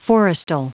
Prononciation du mot forestal en anglais (fichier audio)
Prononciation du mot : forestal